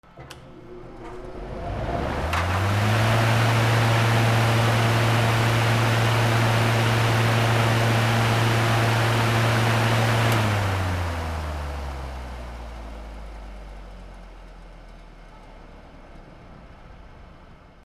換気扇(大)
/ M｜他分類 / L10 ｜電化製品・機械